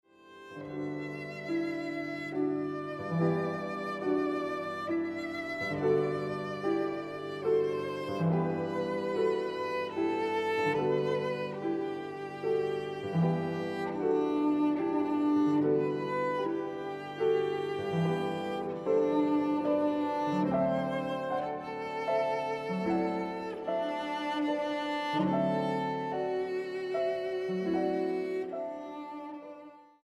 Grabado del 2 al 6 de Septiembre de 2013, Sala Xochipilli
Piano: Bechstein